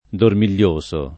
[ dormil’l’ 1S o ]